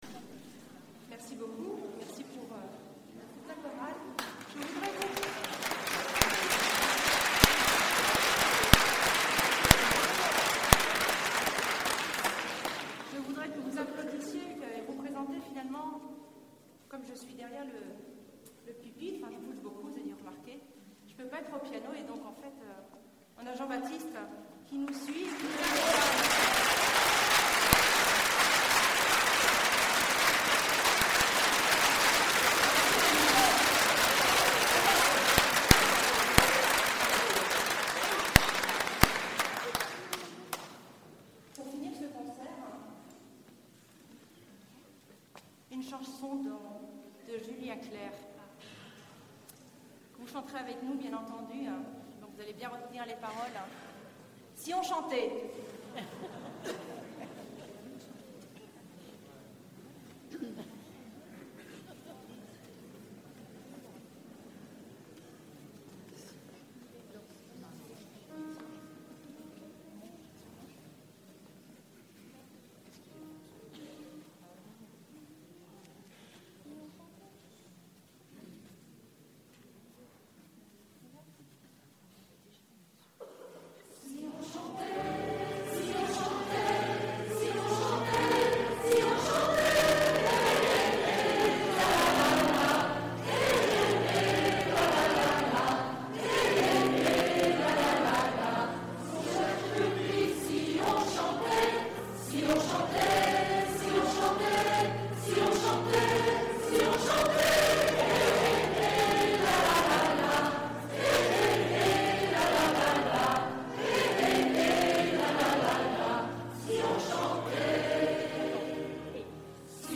Concert à l’église : Après les images, la vidéo, voici le son..